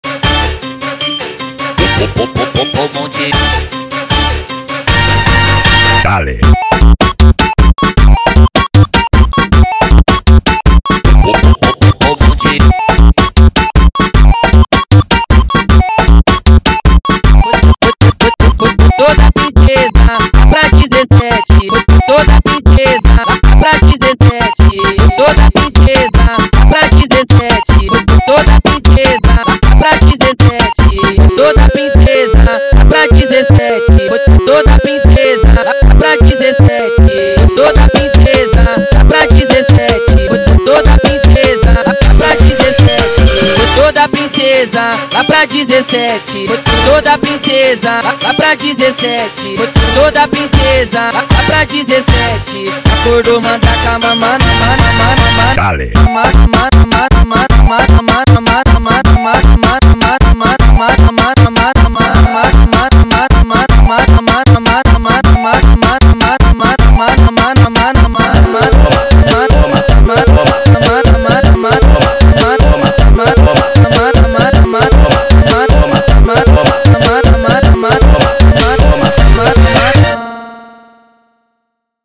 دانلود فانک شاد با ریتم جذاب مناسب ادیت
فانک